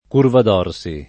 [ kurvad 0 r S i ]